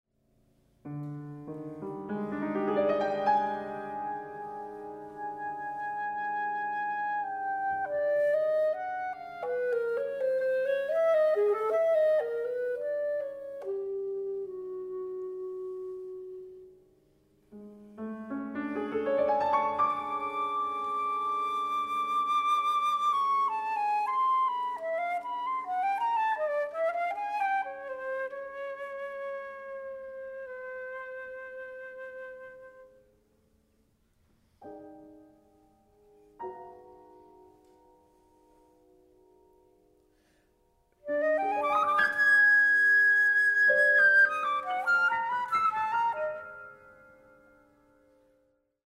flute/bass or alto flute, clarinet/bass clarinet, piano